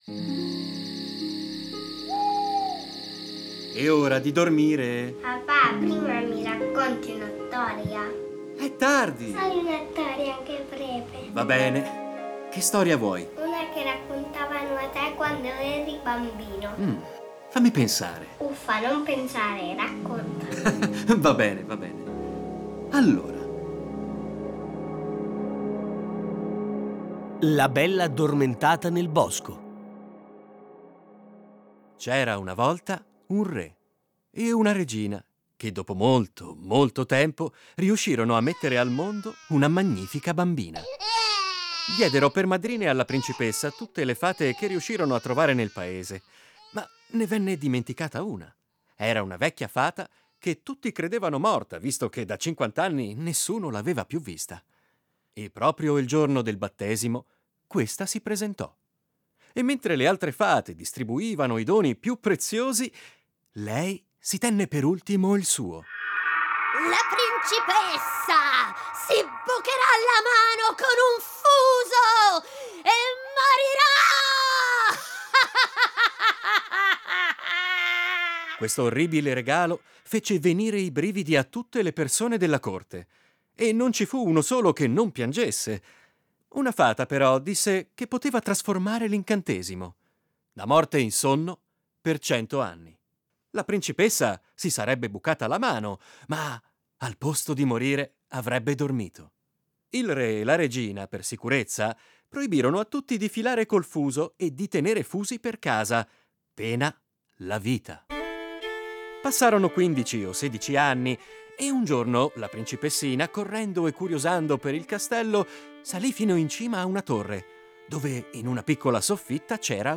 Fiabe
A partire dai testi originali un adattamento radiofonico per fare vivere i bambini storie conosciute, ma un po' dimenticate.